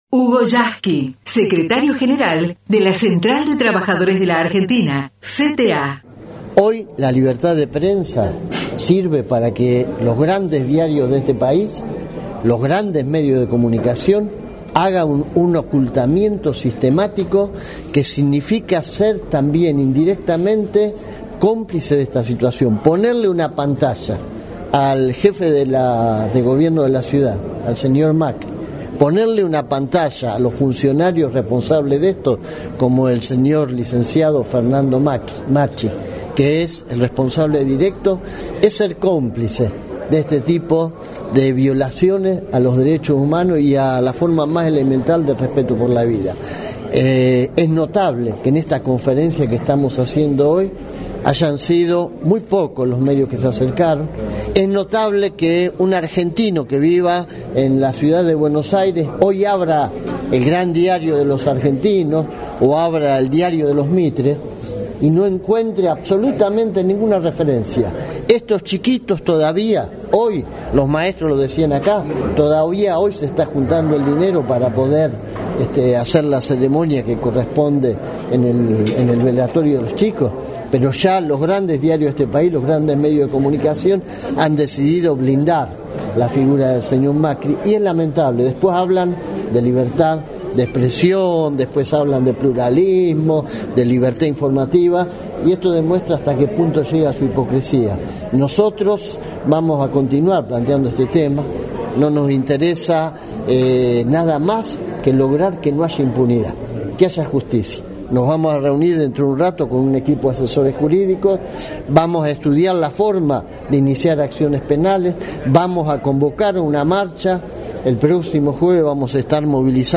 Hugo Yasky en diálogo con la TV Pública y con Prensa CTA tras la conferencia de prensa
El compañero Hugo Yasky, en diálogo con la TV Pública, manifestó:
final_entrevista_yasky.mp3